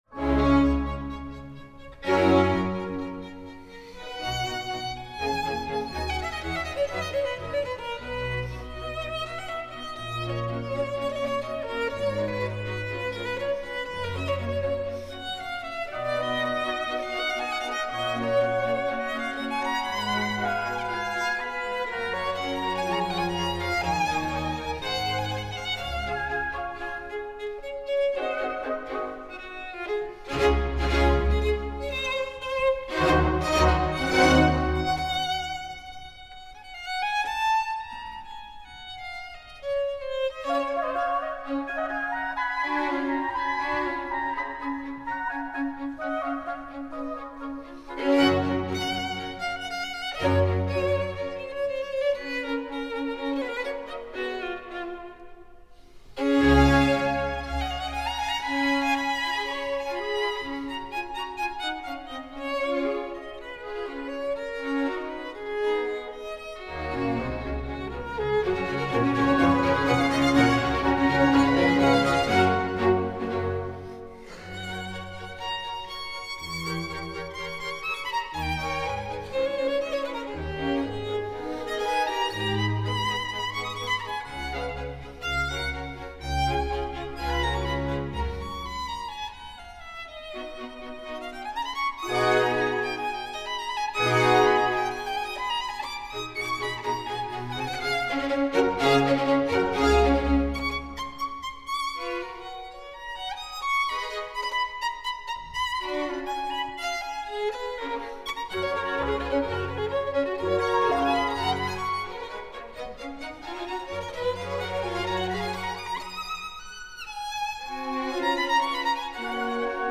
9.2.2014, Helsinki
Allegro – Adagio – Rondeau
joht. Erich Höbarth, viulu